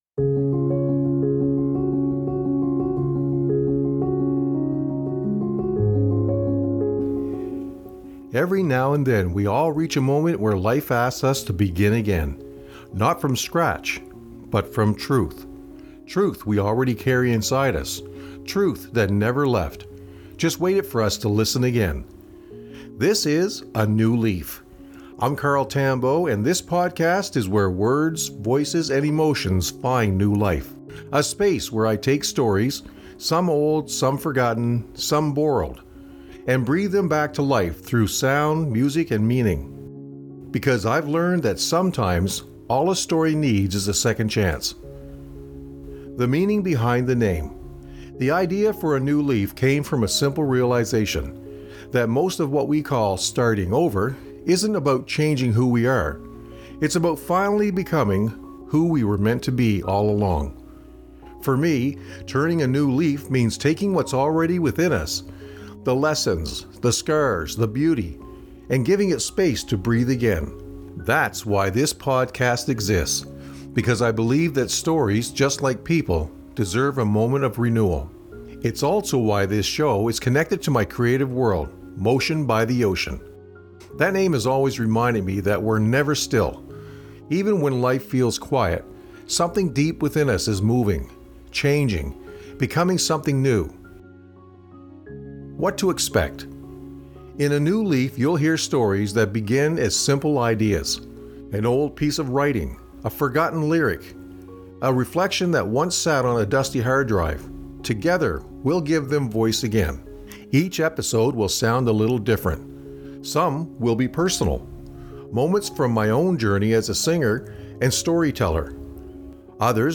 This episode blends authentic storytelling, gentle narration, and the calm rhythm of the ocean to introduce the themes that will guide the series: mindset shifts, resilience, letting go, and turning life’s forgotten pages into something new.